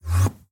Sound / Minecraft / mob / endermen / portal2.ogg